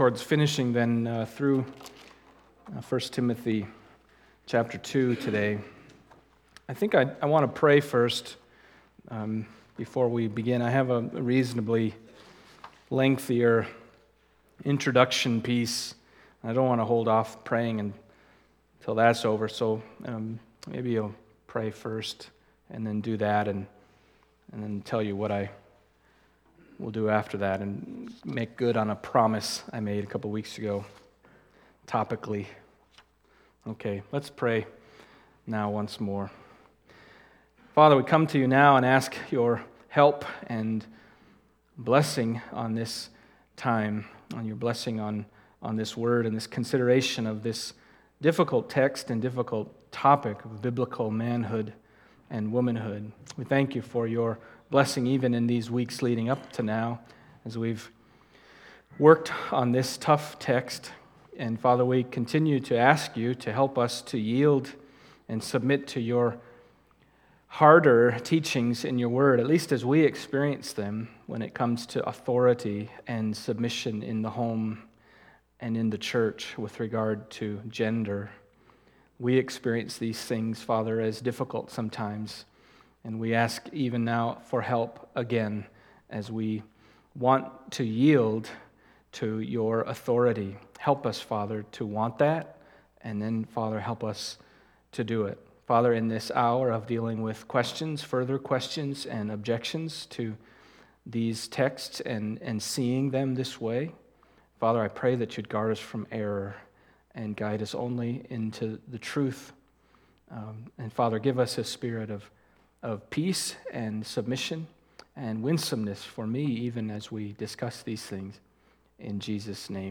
Topical sermon arising from 1 Timothy 2:11-15